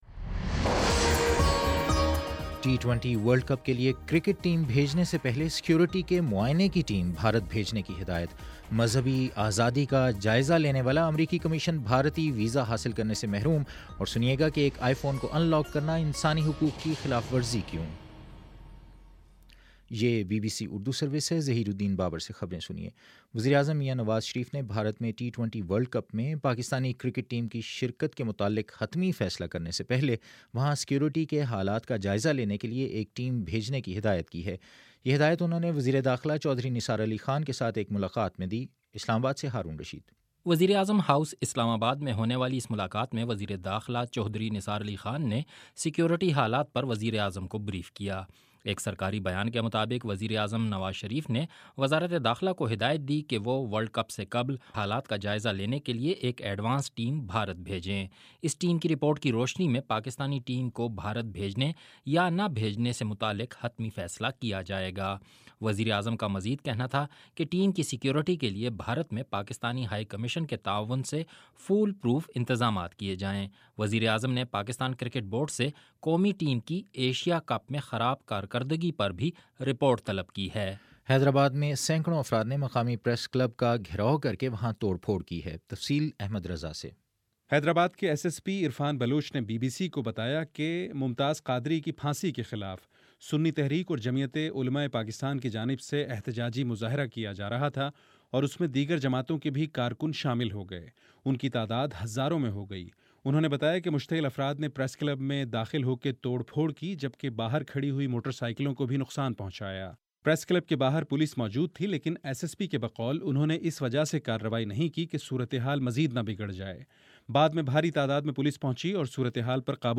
مارچ 04: شام سات بجے کا نیوز بُلیٹن